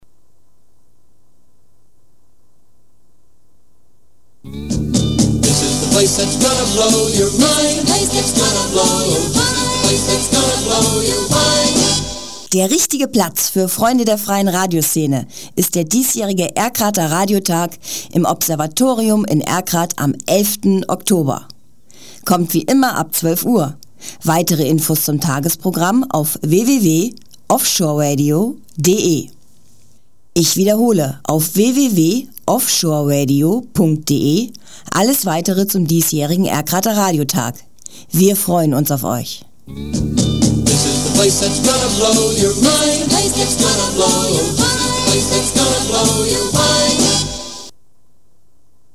Hier der Spot zum 14. Radiotag in Erkrath.